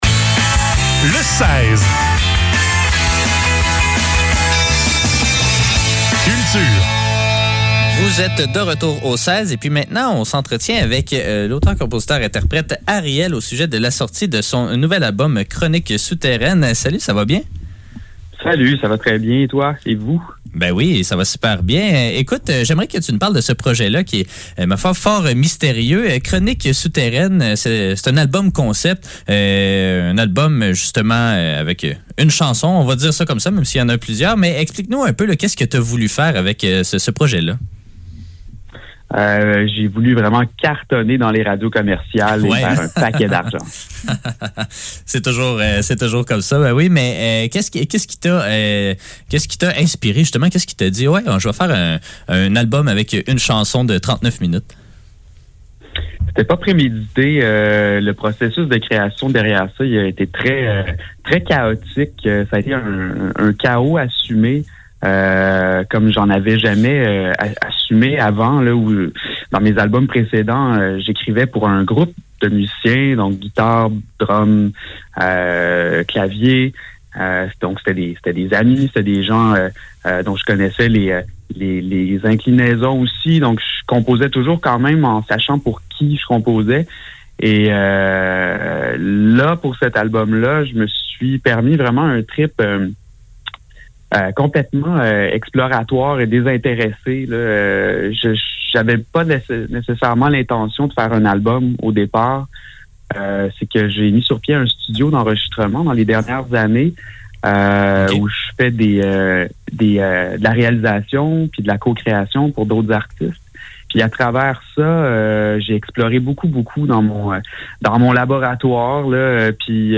Le seize - Entrevue